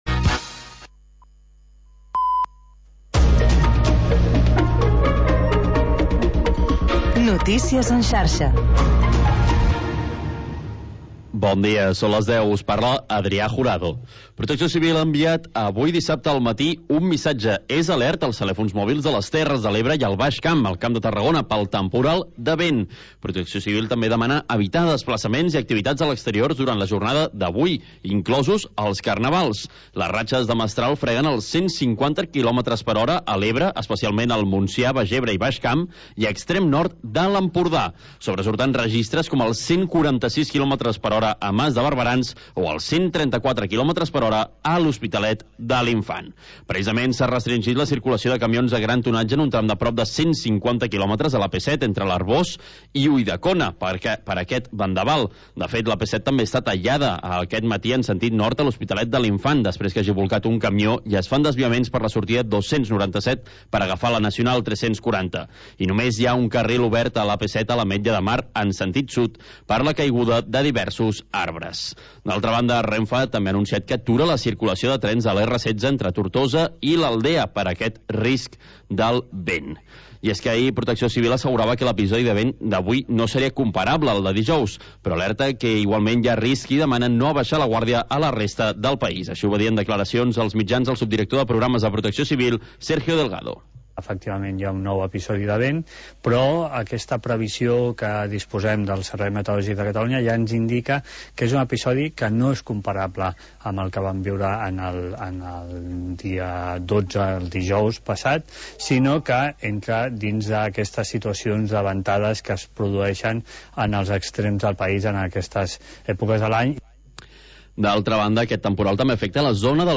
Programa sardanista, amb actualitat, compositors i agenda de ballades